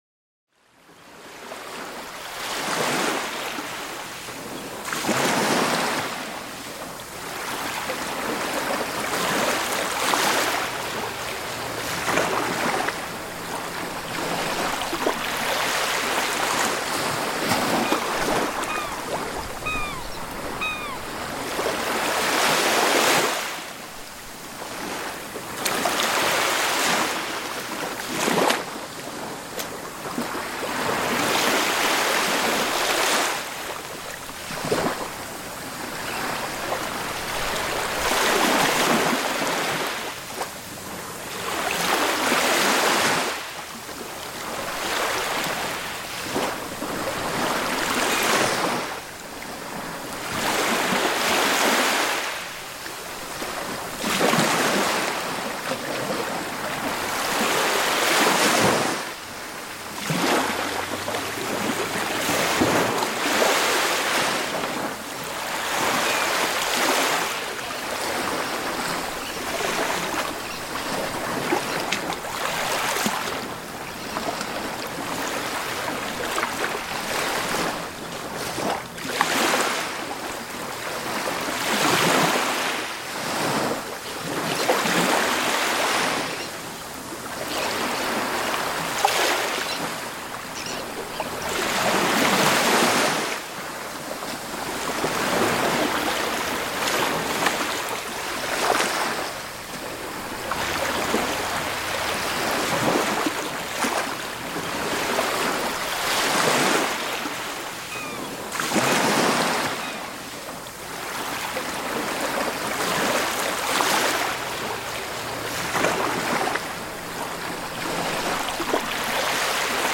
Sonnenuntergang & Wellen Sanfte Natur-Sounds 3h | Schlaf & Ruhe